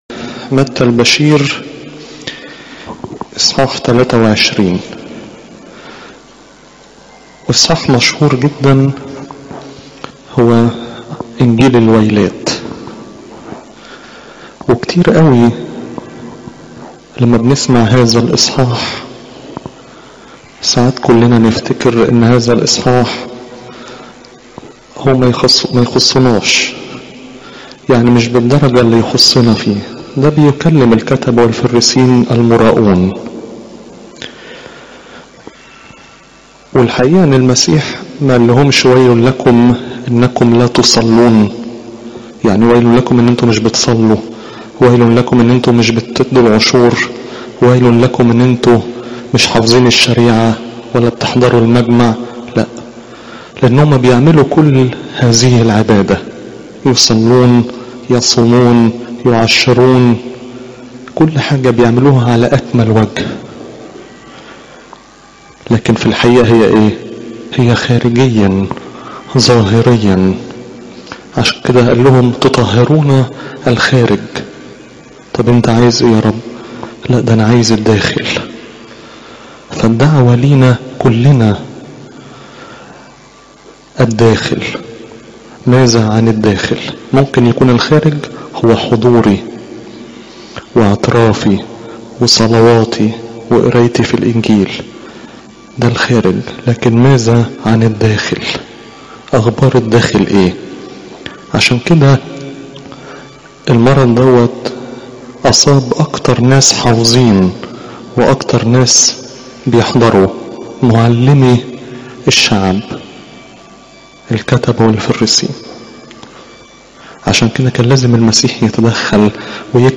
عظات قداسات الكنيسة (مت 23 : 14 - 36)